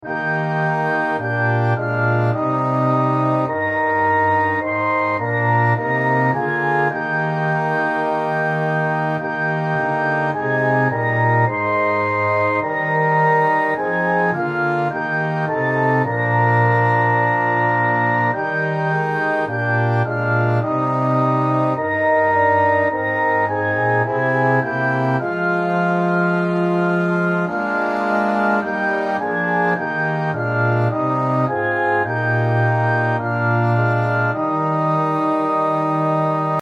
Orchestral Version